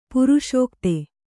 ♪ paruṣōkte